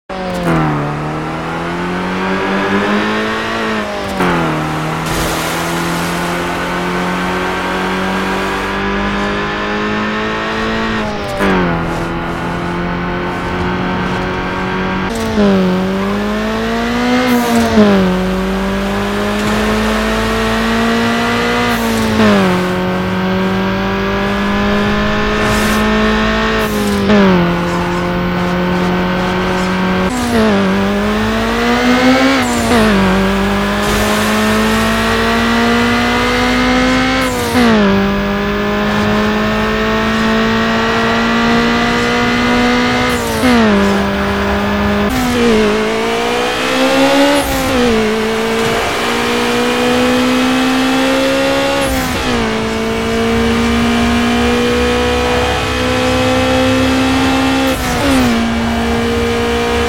Acura Integra Type R in sound effects free download
Acura Integra Type R in Need for Speed Underground